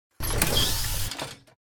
Звуки дверей поезда